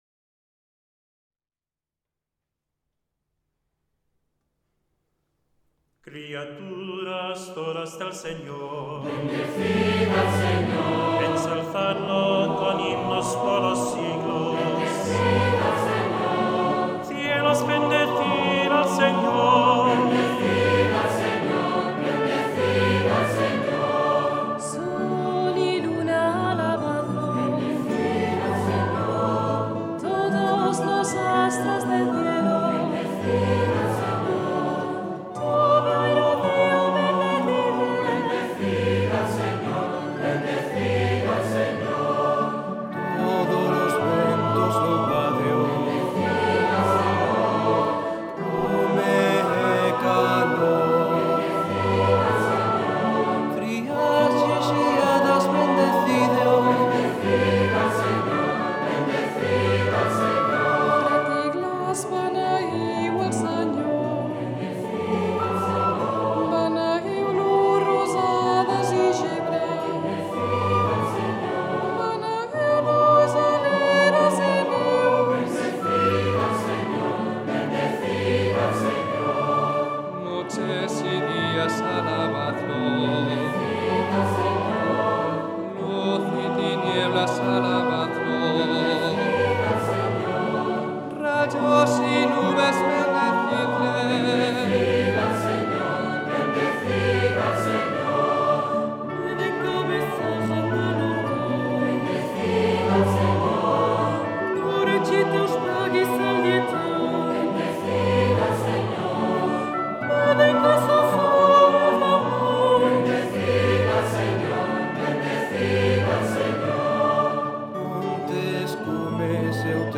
Canto: